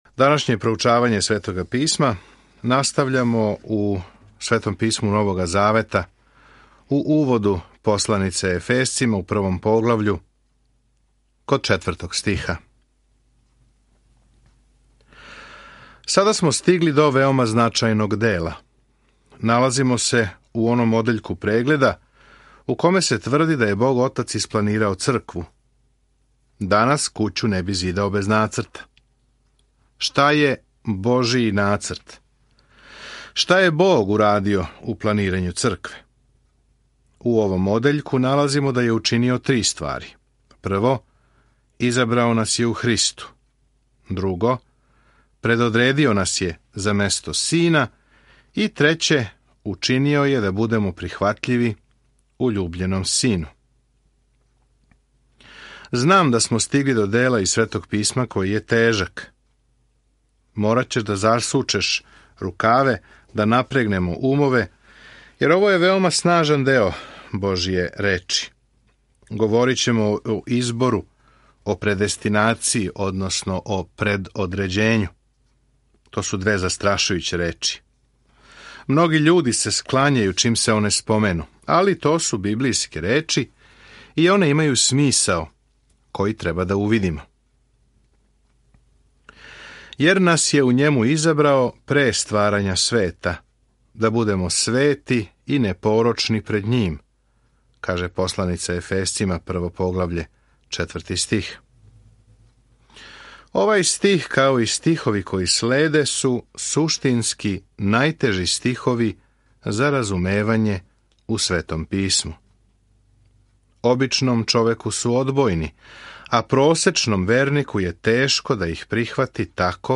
Свакодневно путујте Ефесцима док слушате аудио студију и читате одабране стихове из Божје речи.